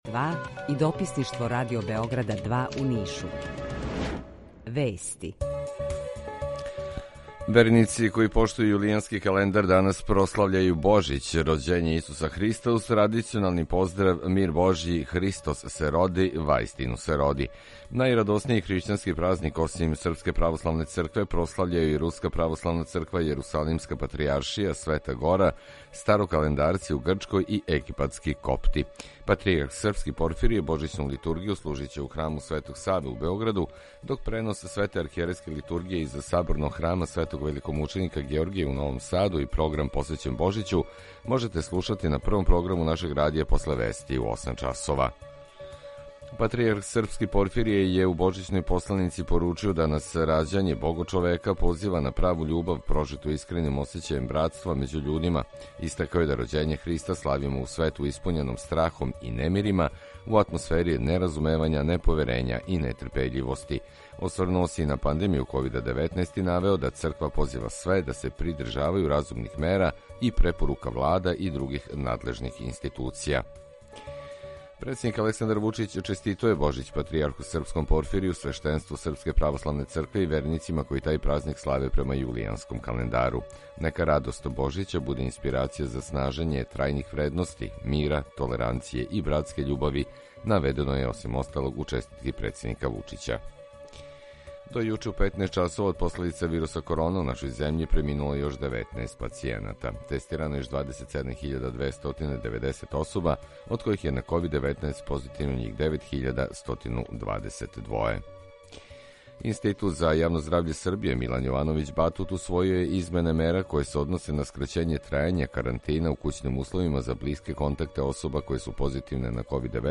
Емисију реализујемо са студијом Радија Републике Српске у Бањалуци и Радијом Нови Сад
Јутарњи програм из три студија
У два сата, ту је и добра музика, другачија у односу на остале радио-станице.